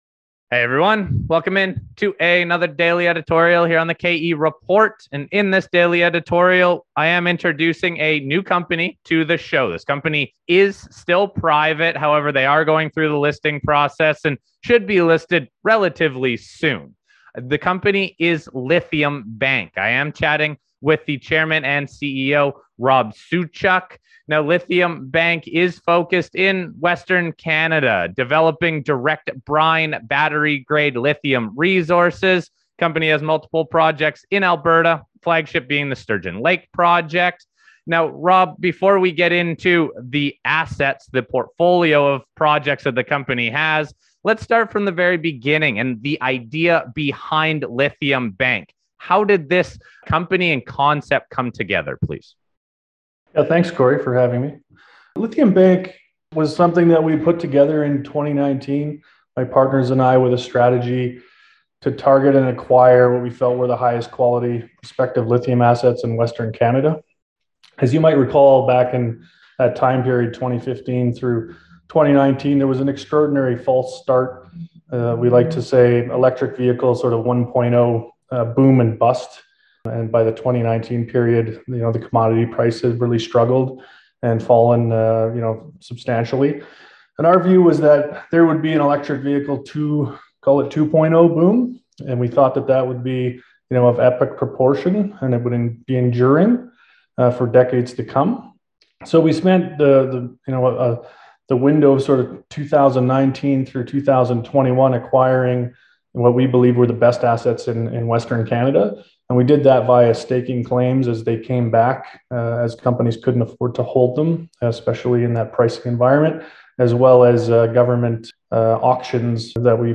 Interview Disclaimer Note – This interview was recorded a couple weeks ago but we held off on posting until the listing was live.